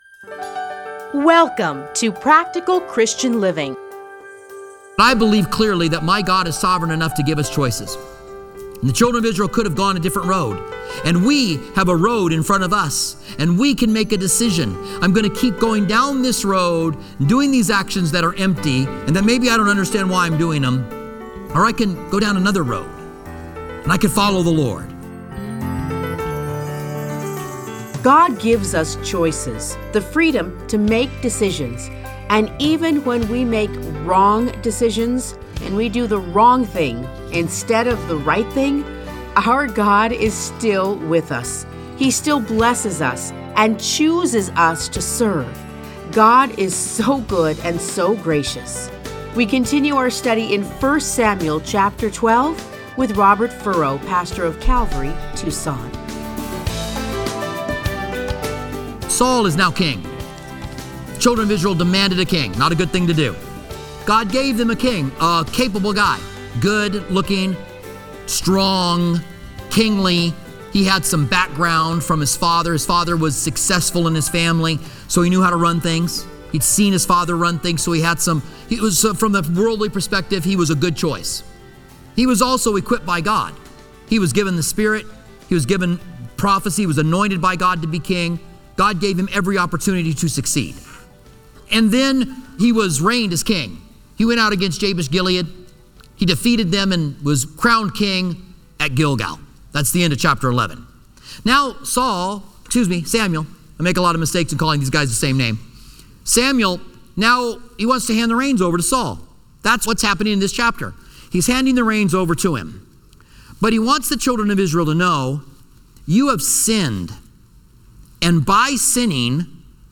Listen to a teaching from 1 Samuel 12:1-25.